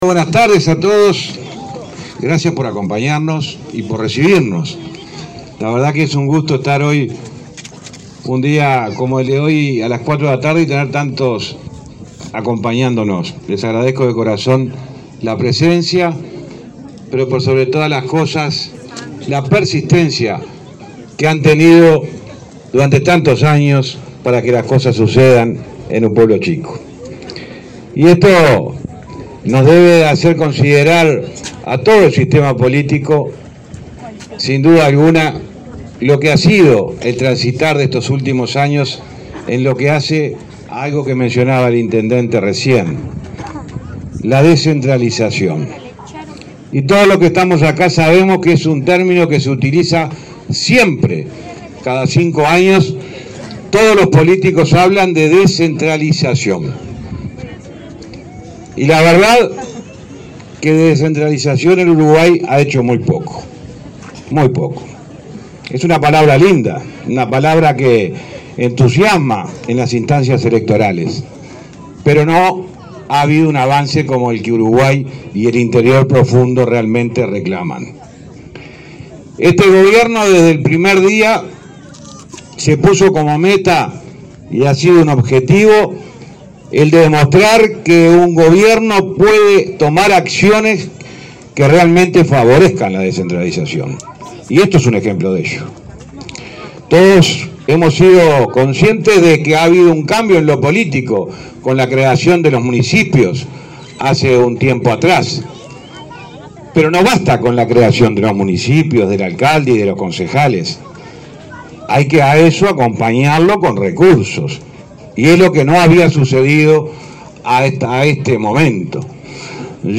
Palabras del ministro de Transporte, José Luis Falero, en Quebracho
El ministro de Transporte, José Luis Falero, inauguró la rotonda de ingreso a Quebracho, en el departamento de Paysandú.